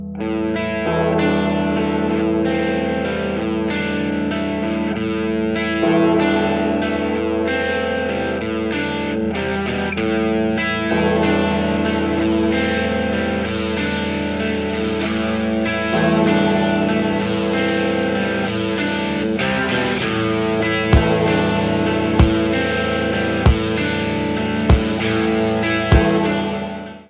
• Category Rock